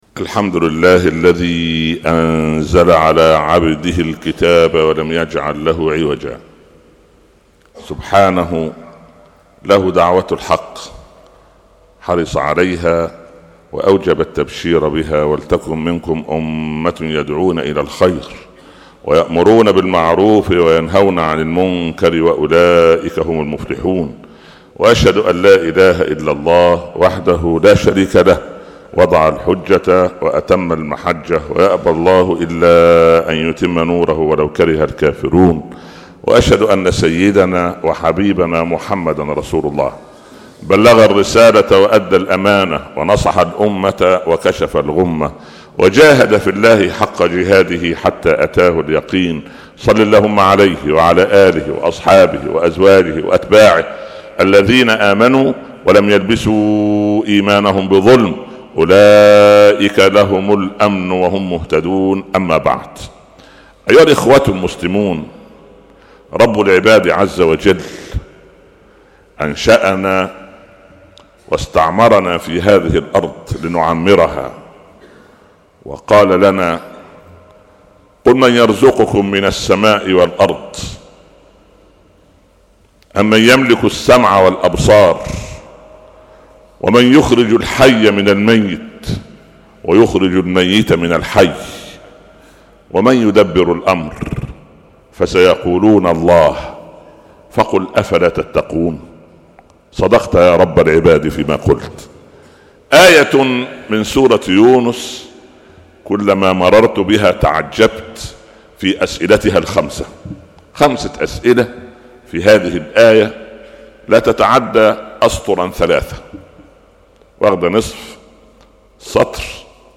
khotab-download-87012.htm